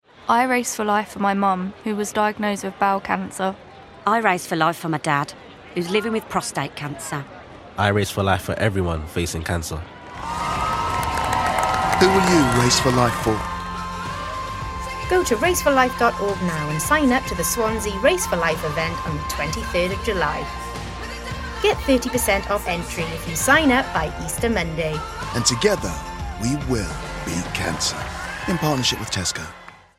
Swansea / Welsh accent